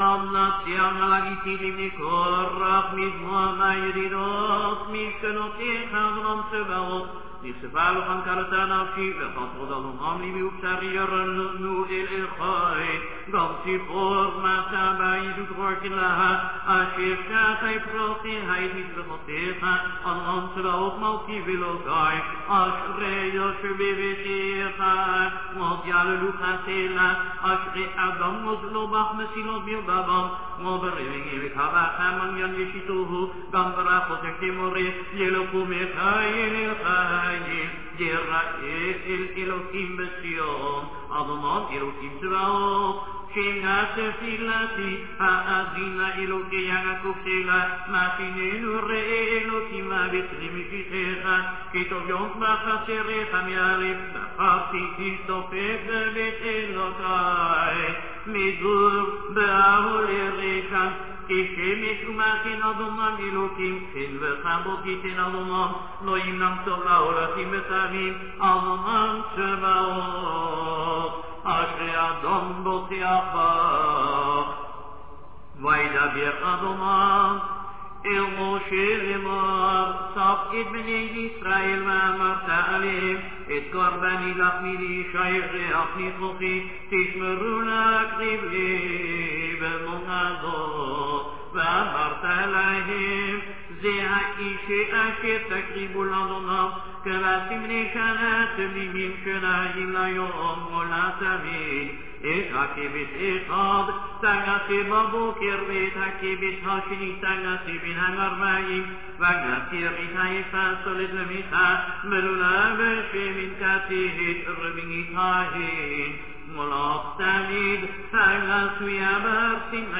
Working day’s melody, Chazzan is sitting
No repetition, Chazzan starts with semi loud voice till the Kedusha.
Chazzan & congregation